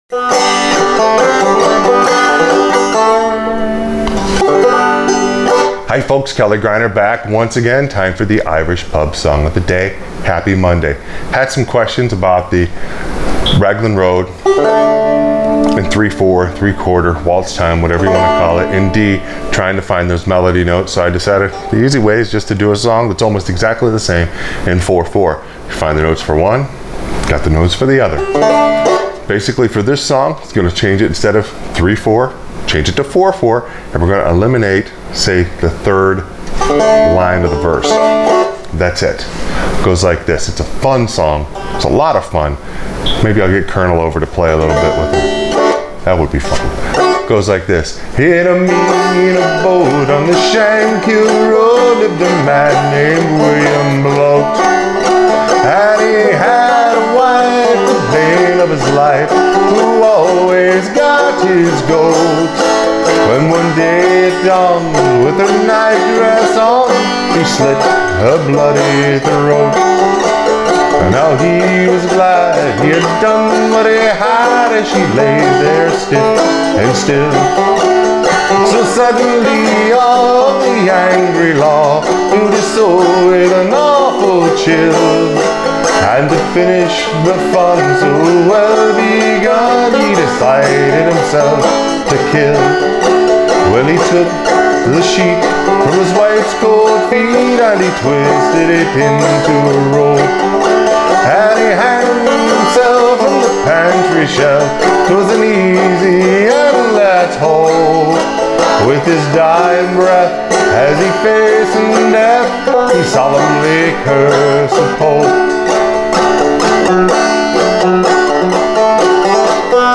Irish Pub Song Of The Day – The Ballad Of William Bloat on Frailing Banjo
Clawhammer BanjoFrailing BanjoInstructionIrish Pub Song Of The Day
All you need to do is change Raglan Road to 4/4 time from 3/4 time and eliminate the third line of the verse.